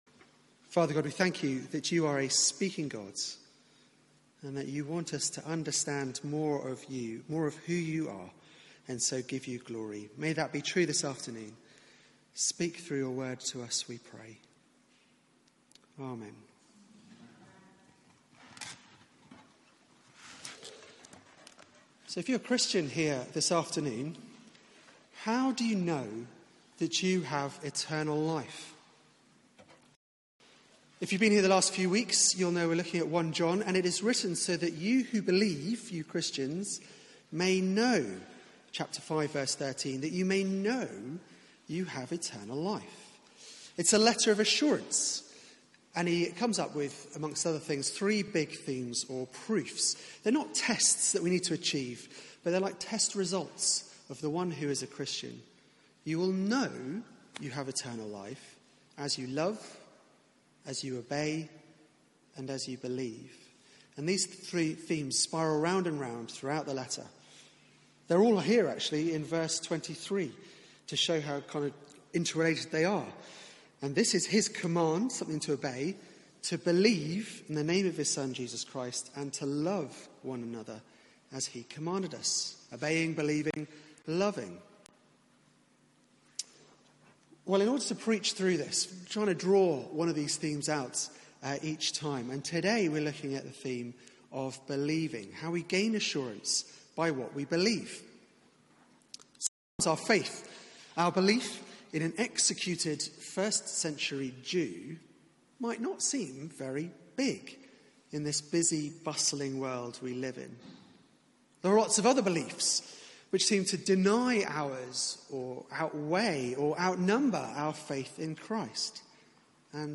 Media for 4pm Service on Sun 06th May 2018 16:00 Speaker